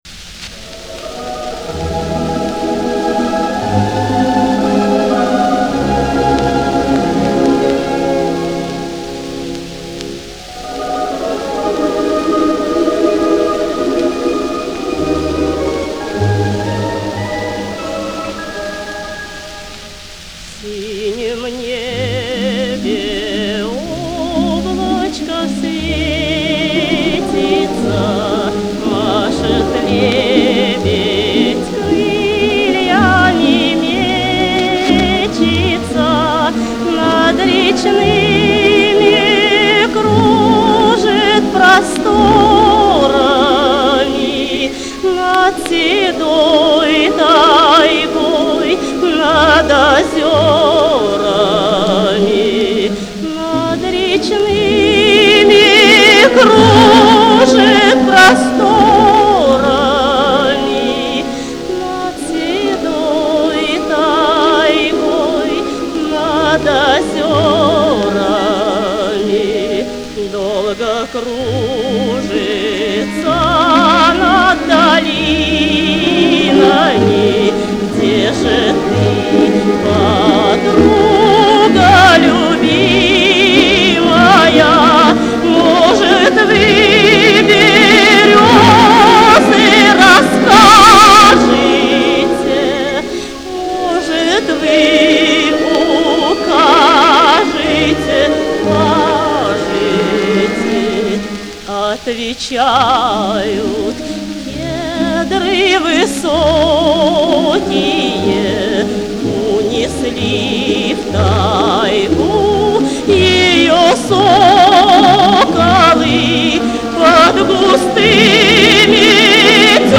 Оцифровка пластинки на 78 об\мин